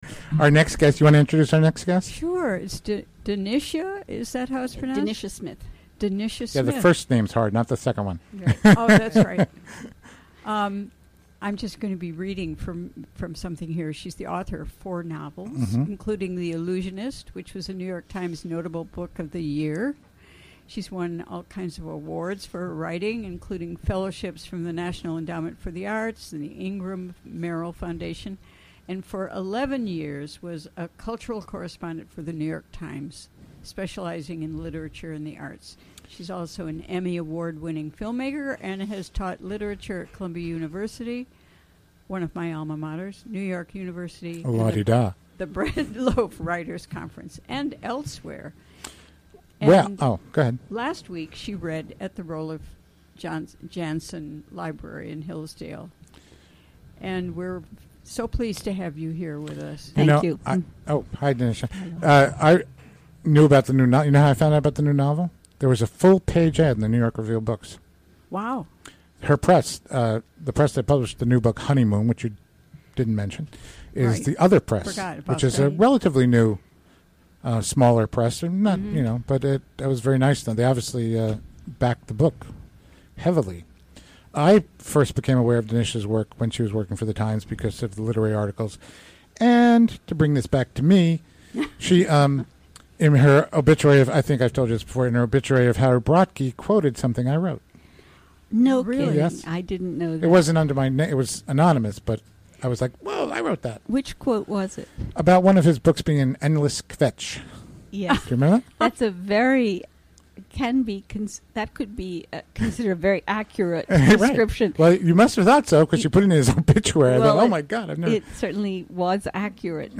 Recorded during the WGXC Afternoon Show on Thursday July 14, 2016.